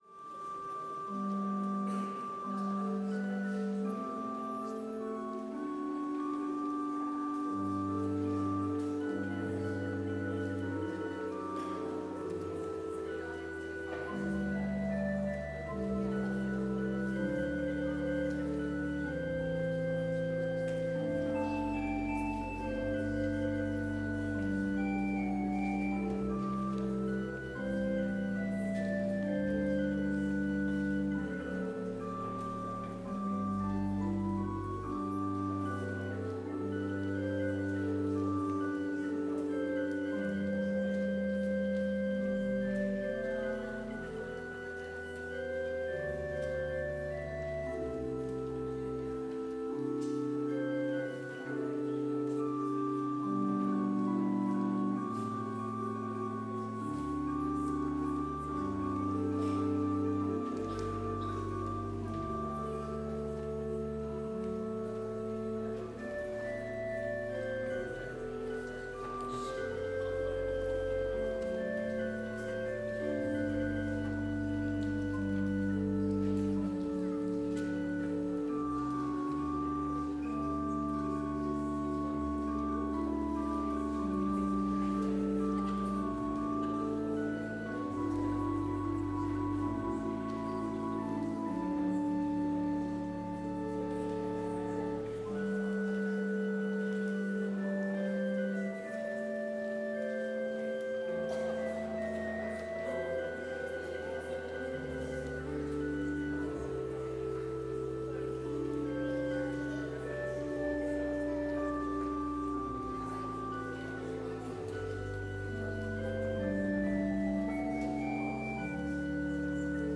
Complete service audio for Lenten Vespers - Wednesday, March 19, 2025